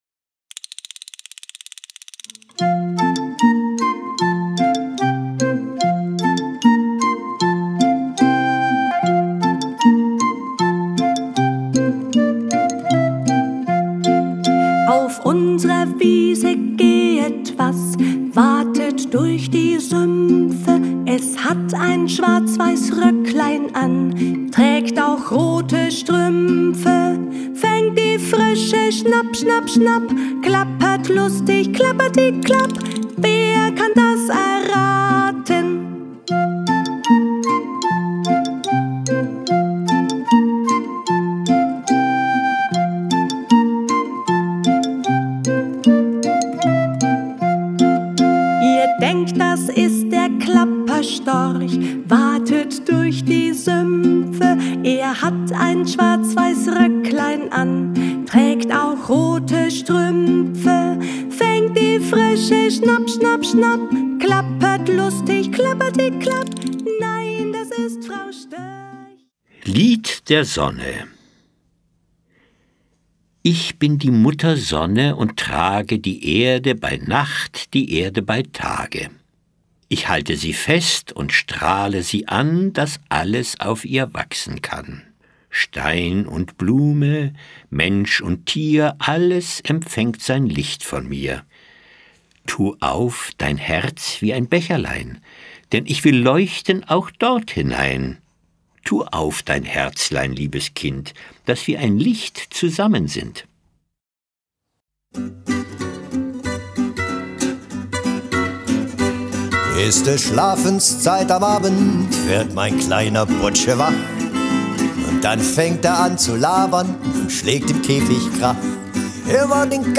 Schlagworte Kinderlieder • Kurzgeschichten • Ostern